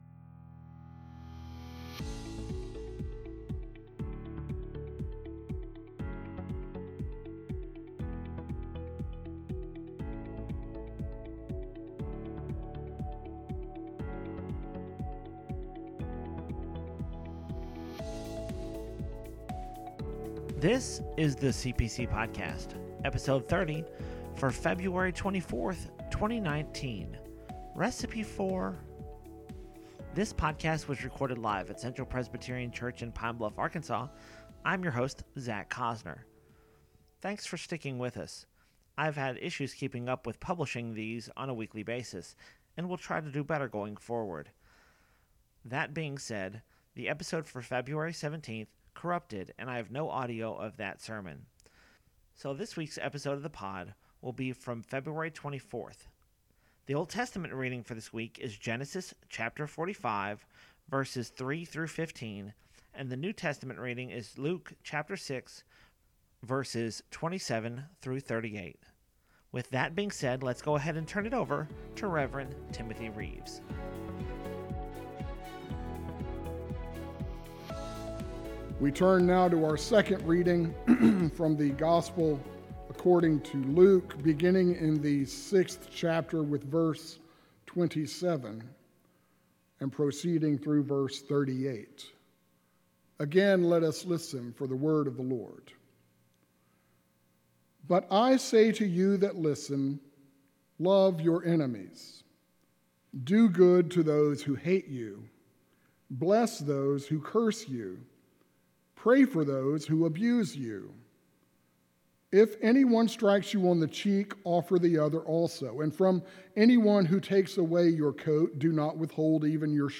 So here’s the Sermon for 2-24-19.